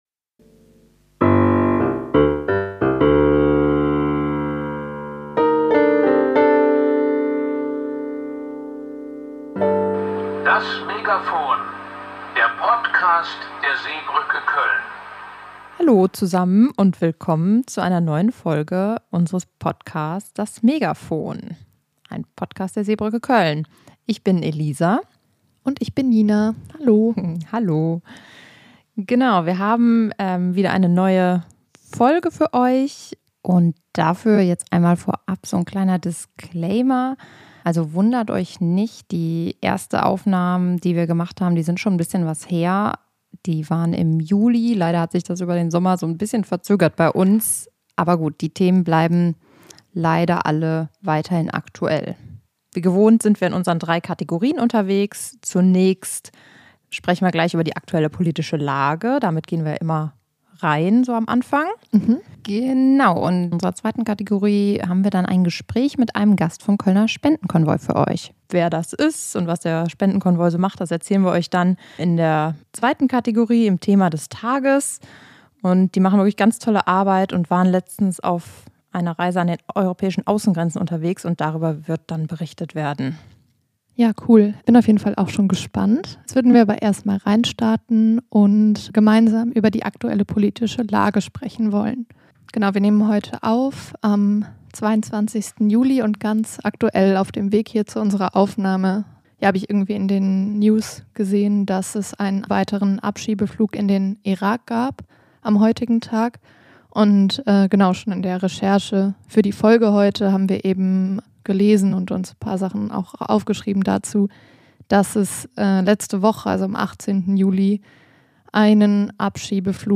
In dieser Folge haben wir unseren ersten Gast vom Kölner Spendenkonvoi zu Besuch. Er erzählt über dessen Entstehung sowie von deren letzten Reise an eine europäische Außengrenze nach Bulgarien und den Zuständen vor Ort.